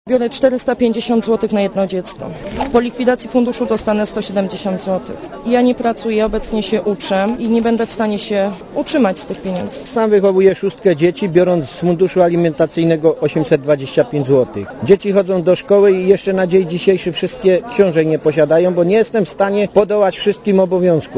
To dla nas ogromna tragedia, bo juz teraz żyjemy w skrajnej nędzy - tak nowe przepisy komentują rodzice korzystający do tej pory z funduszu alimentacyjnego - posłuchaj